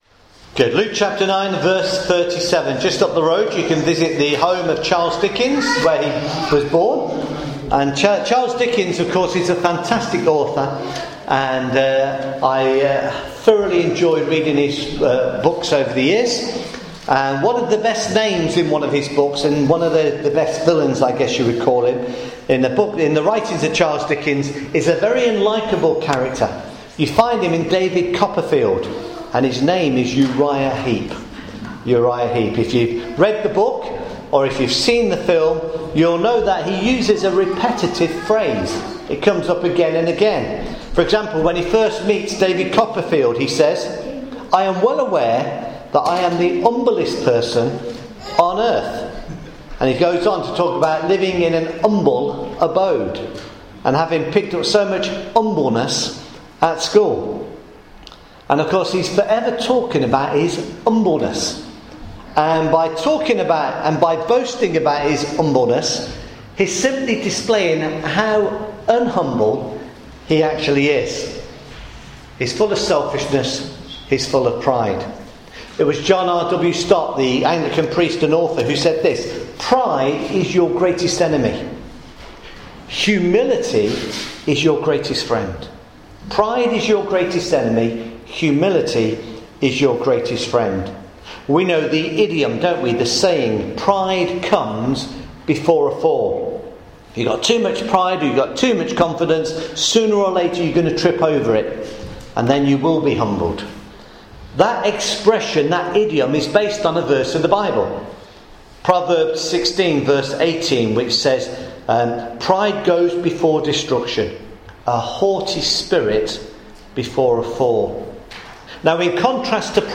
Lessons in Humility – Luke chapter 9 verses 37-50 – Sermon